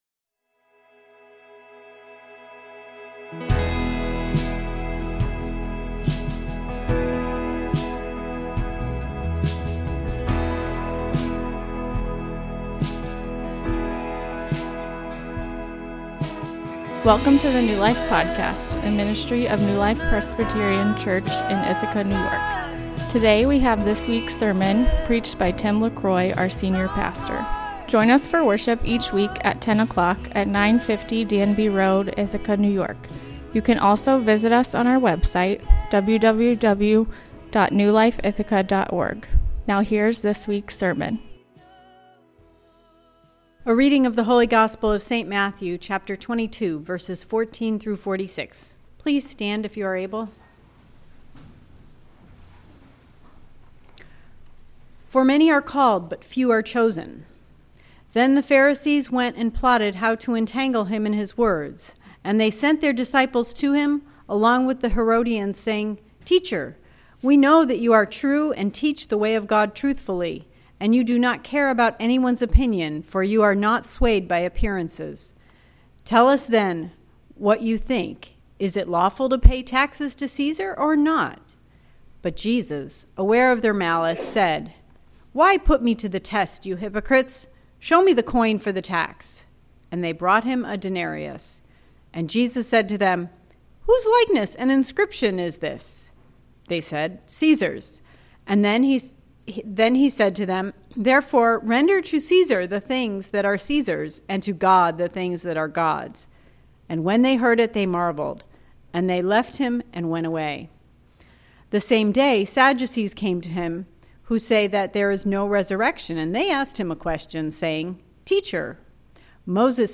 In this sermon we explore whether there is on political party that is inherently Christian, or whether Christians fit in to the two party system at all.